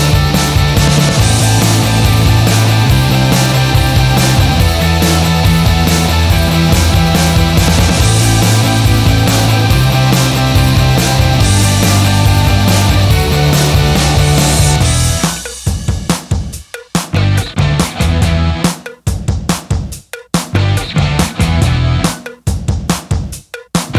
no Backing Vocals Rock 3:31 Buy £1.50